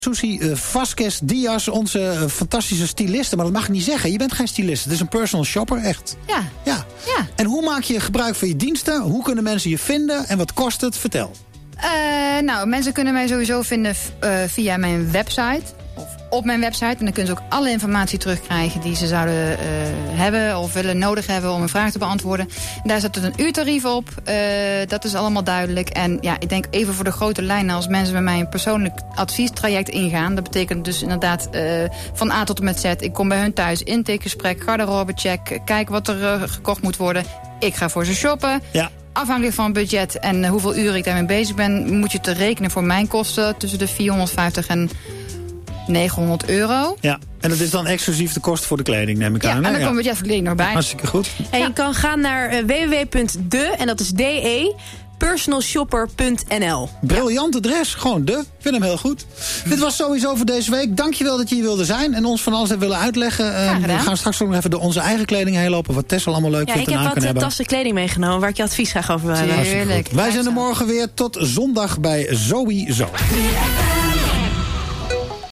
Media: 3FM Programma: ZoëYZO (BNN)